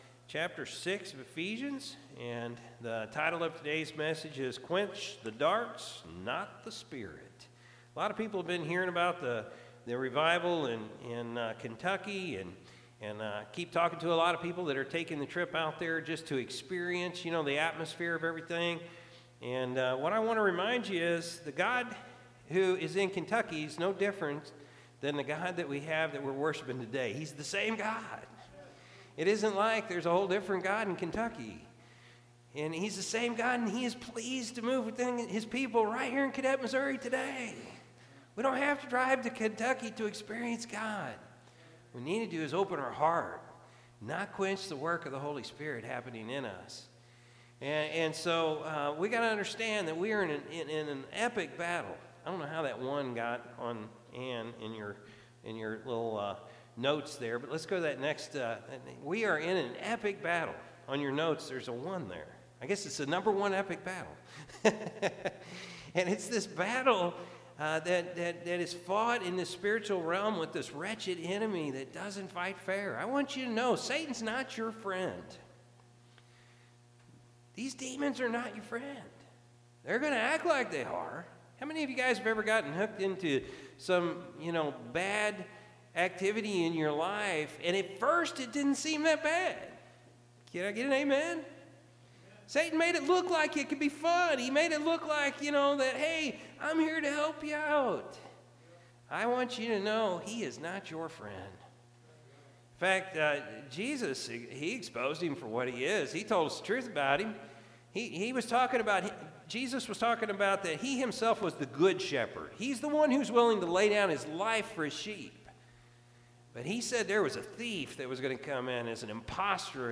March-5-2023-Morning-Service.mp3